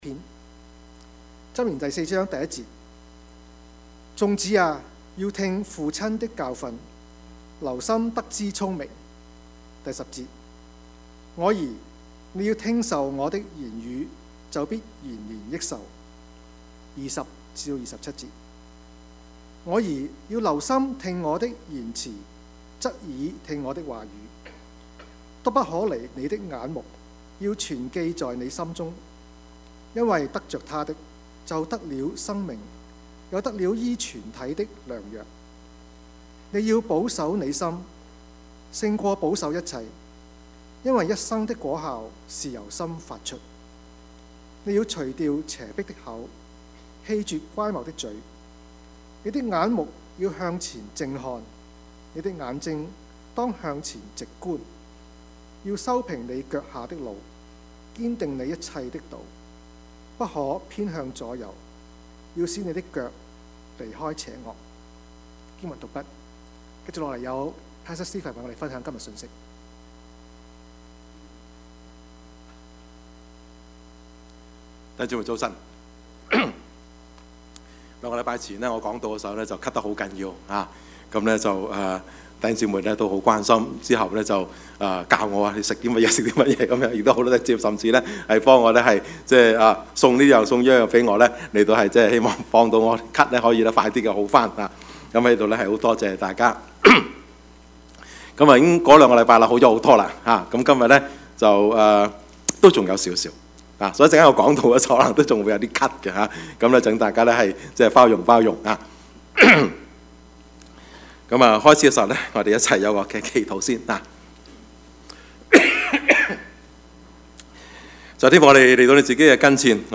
Service Type: 主日崇拜
Topics: 主日證道 « 取捨、抉擇 計算代價 »